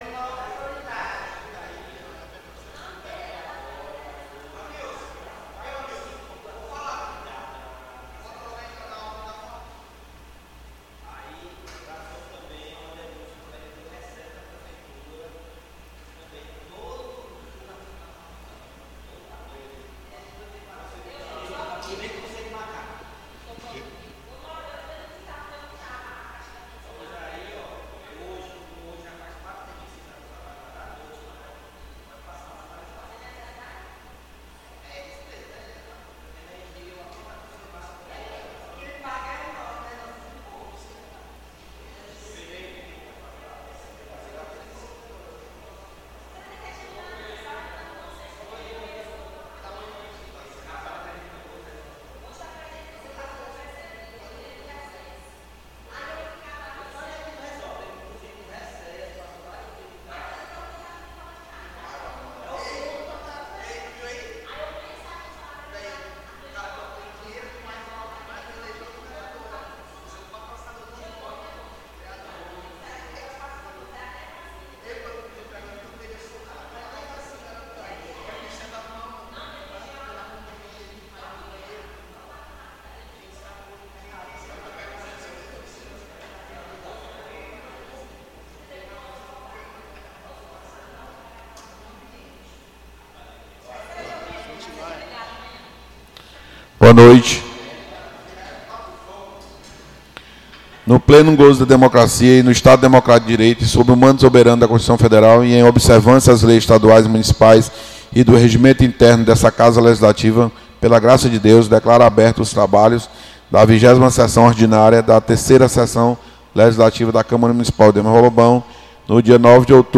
20ª Sessão Ordinária 09 de Outubro